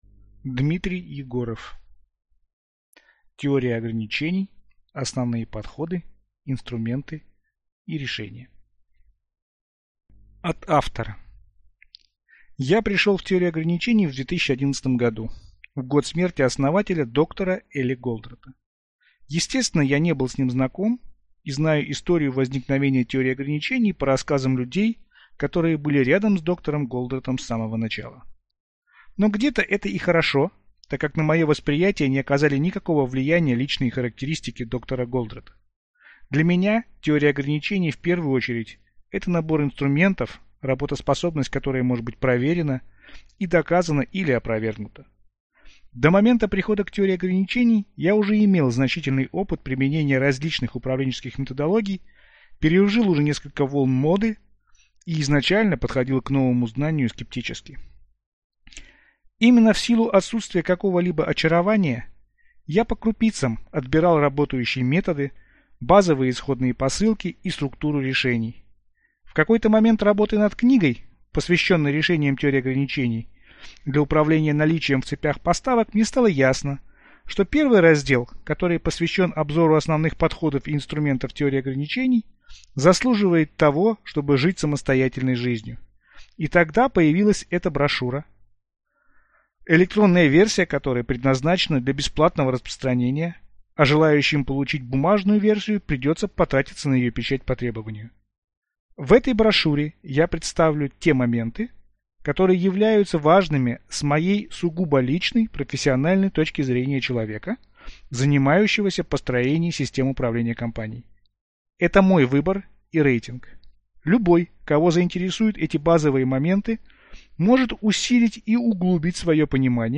Аудиокнига Теория ограничений. Основные подходы, инструменты и решения | Библиотека аудиокниг